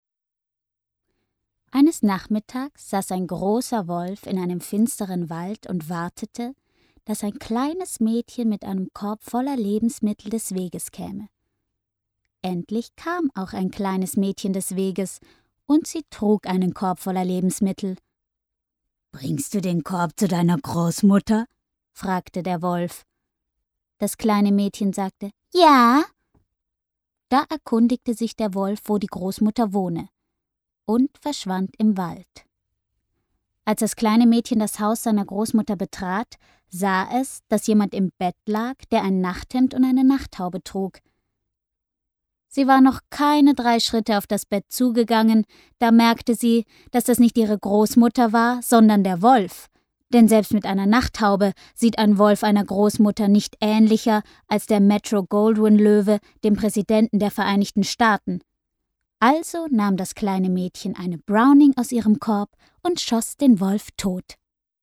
unverwechselbare, spezielle Stimme fĂŒr HĂ¶rspiel, Werbung, HĂ¶rbuch
Sprechprobe: Sonstiges (Muttersprache):